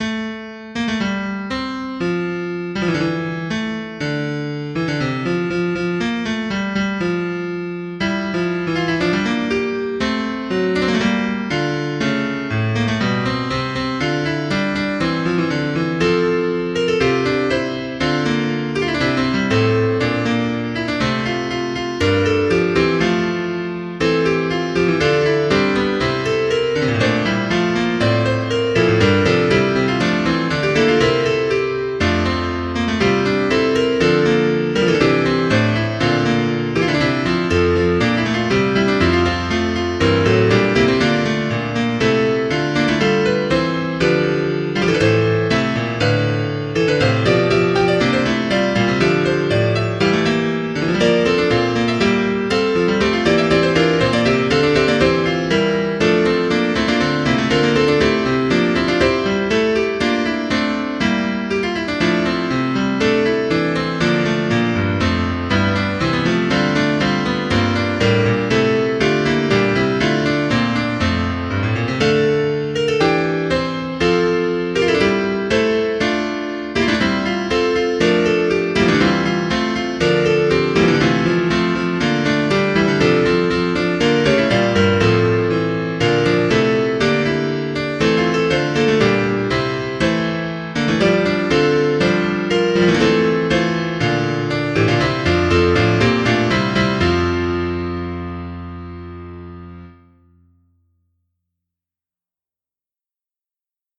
A short fugue, for the piano, written in 1961.